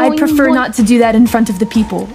Worms speechbanks
Fireball.wav